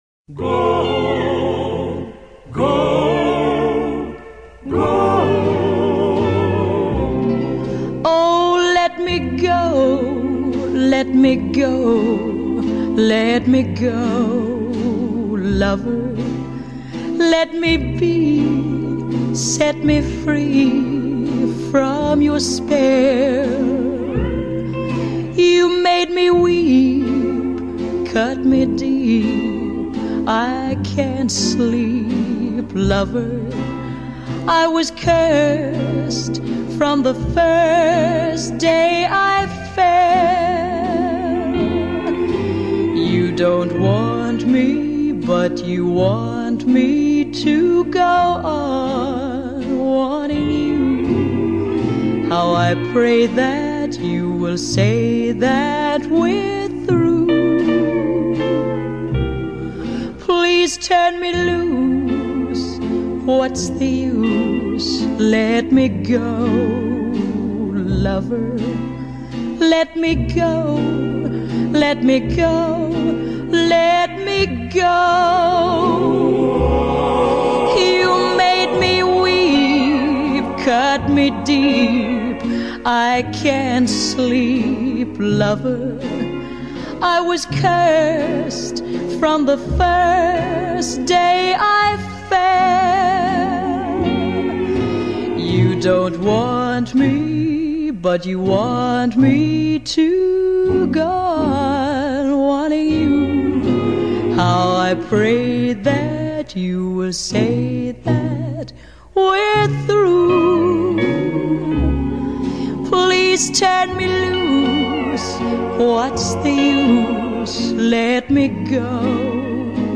凄楚浪漫情歌